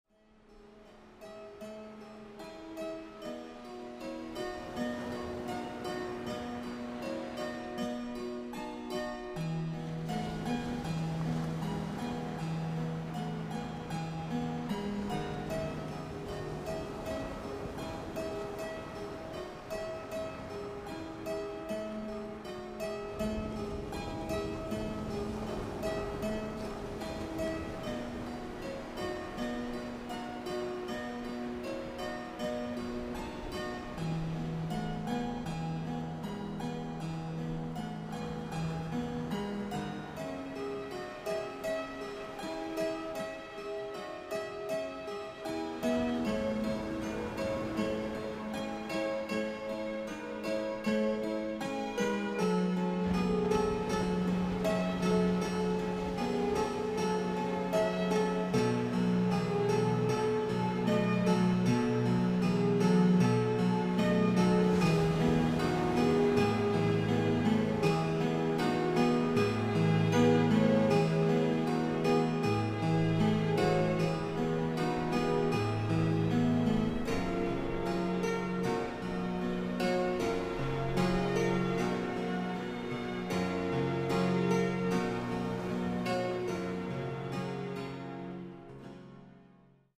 stimulating (neo-)classical music
Sound and music pure